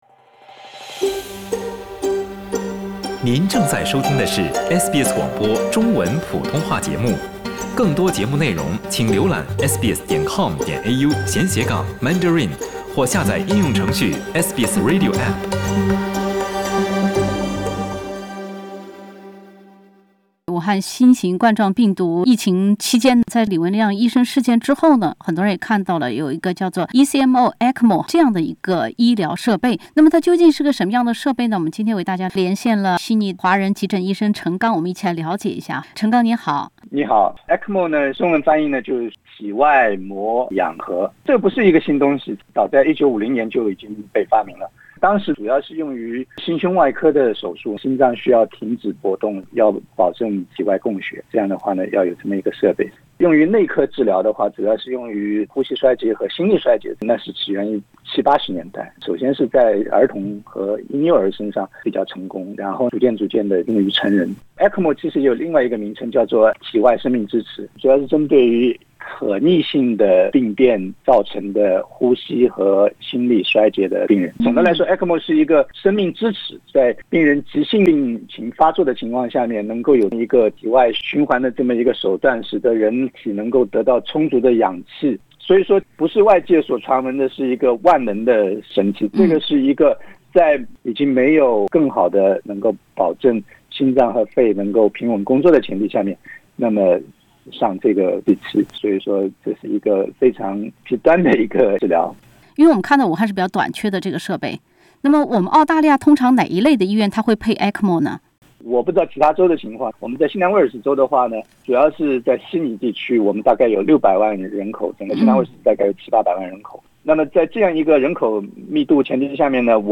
新州急诊科医生给大家讲讲ECMO 怎么工作的。（点击封面图片，收听完整采访）